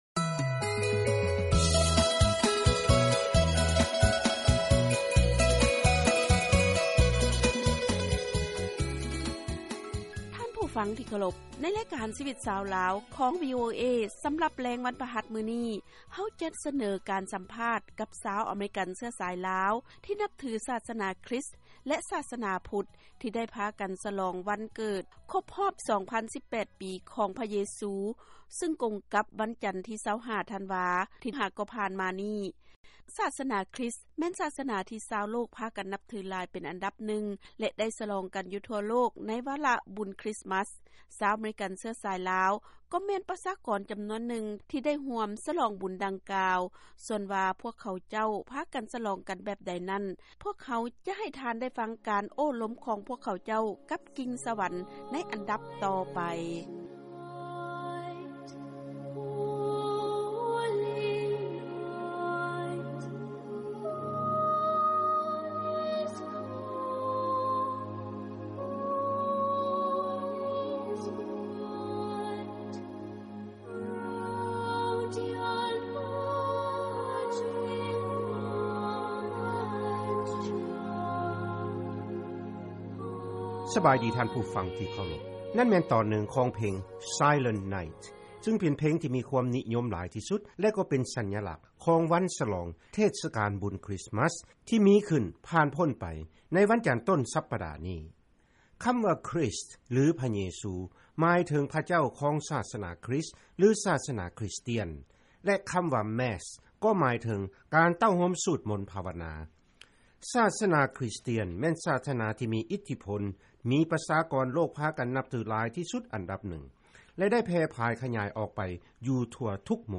ເຊີນຟັງລາຍງານ ຊາວອາເມຣິກາເຊື້ອສາຍລາວ ສະຫລອງບຸນຄຣິສມັສ